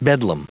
Prononciation du mot bedlam en anglais (fichier audio)
Prononciation du mot : bedlam